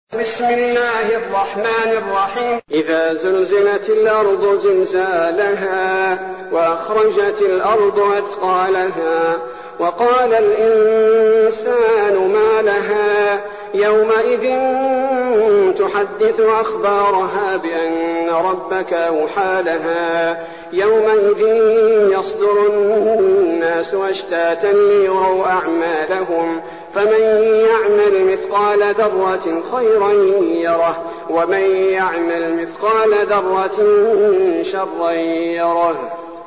المكان: المسجد النبوي الشيخ: فضيلة الشيخ عبدالباري الثبيتي فضيلة الشيخ عبدالباري الثبيتي الزلزلة